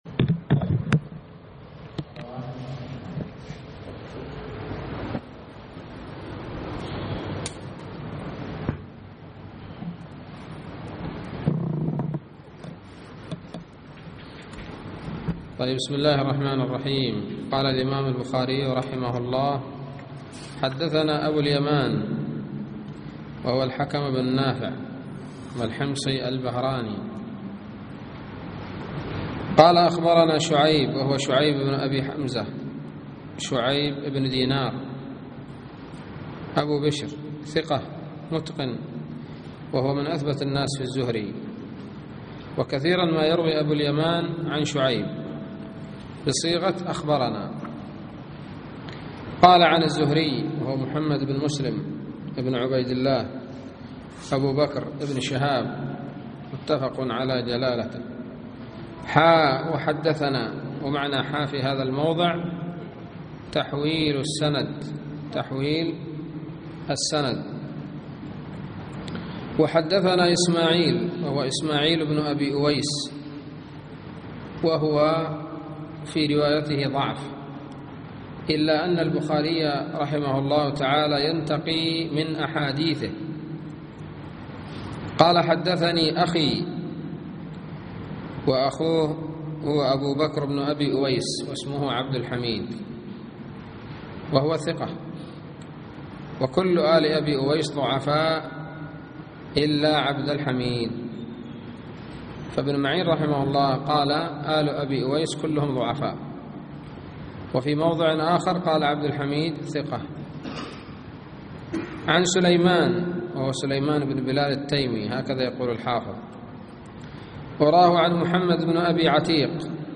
الدرس الرابع عشر من كتاب الجهاد والسير من صحيح الإمام البخاري